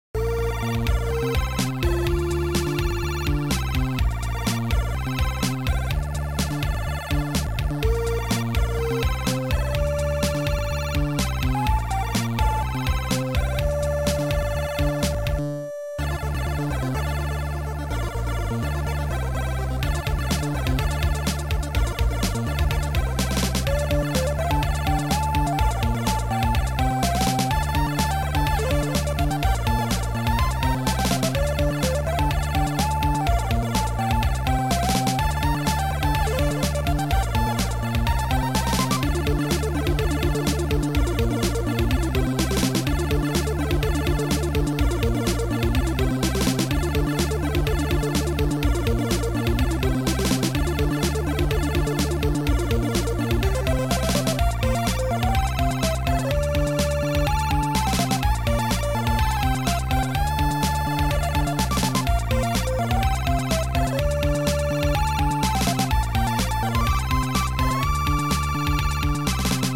Noisetracker/Protracker
Chip Music